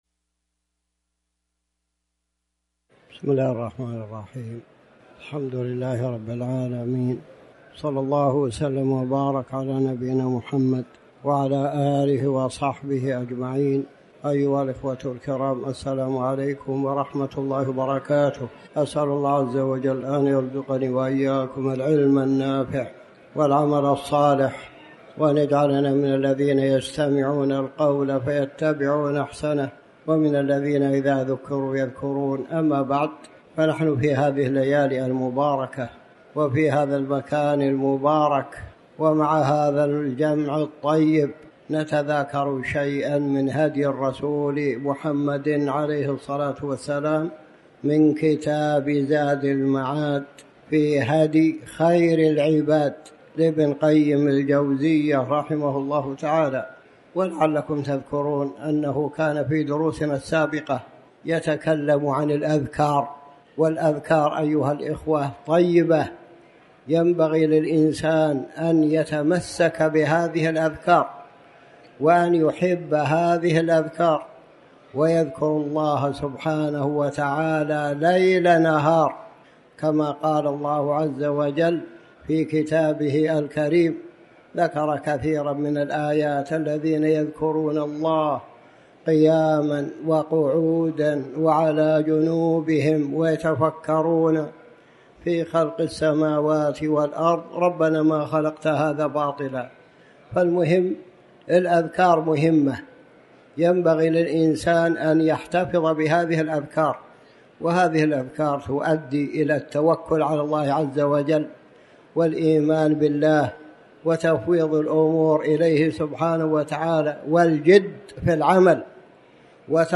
تاريخ النشر ٢٠ ذو الحجة ١٤٤٠ هـ المكان: المسجد الحرام الشيخ